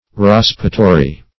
Search Result for " raspatory" : The Collaborative International Dictionary of English v.0.48: Raspatory \Rasp"a*to*ry\ (r[.a]sp"[.a]*t[-o]*r[y^]), n. [LL. raspatorium: cf. F. raspatoir.